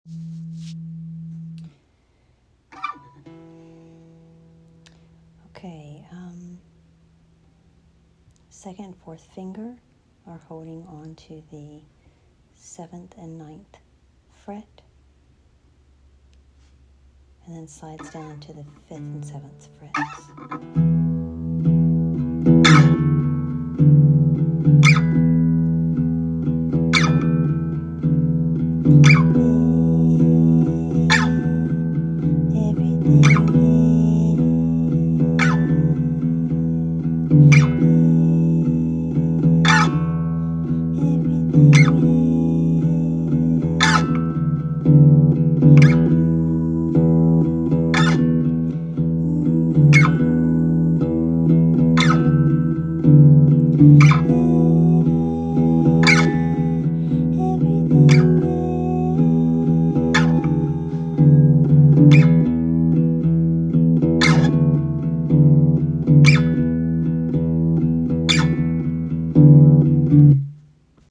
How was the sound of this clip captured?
iphone writing session, unfinished Oct 2024